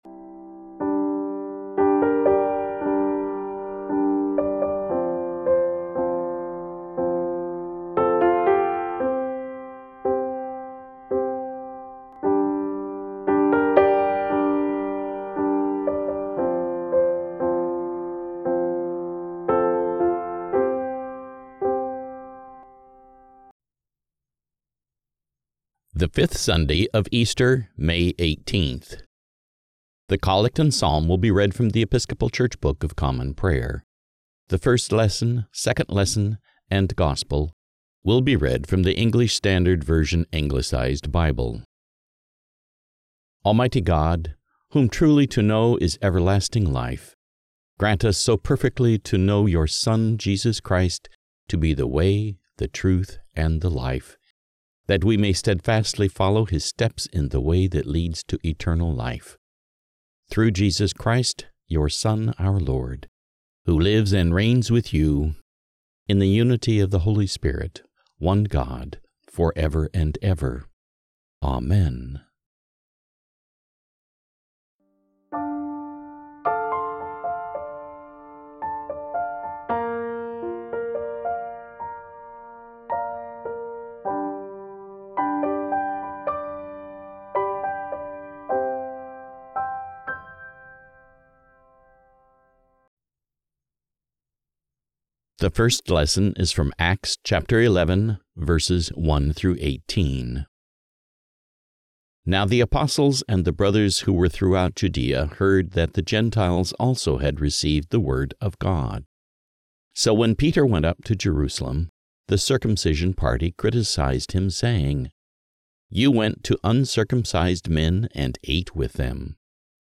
The Collect and Psalm will be read from The Episcopal Church book of Common Prayer
The First Lesson, Second Lesson and Gospel will be read from the English Standard Version Anglicized Bible